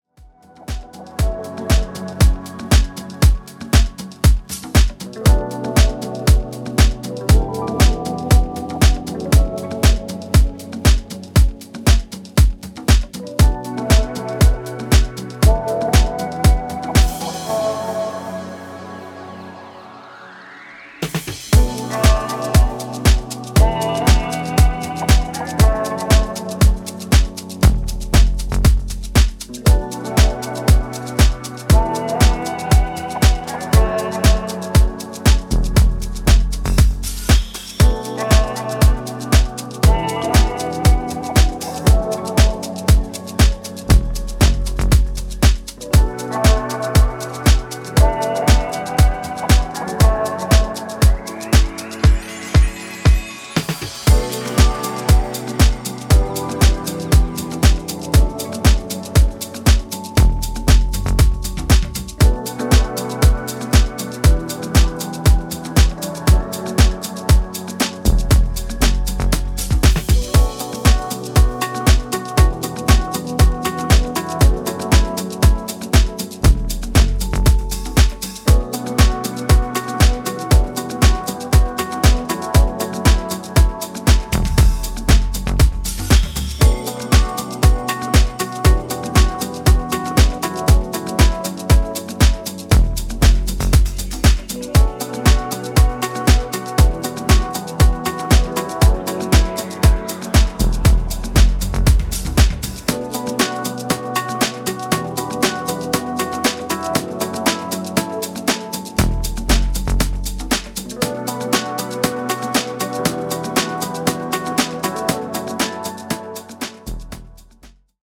ジャンル(スタイル) DEEP HOUSE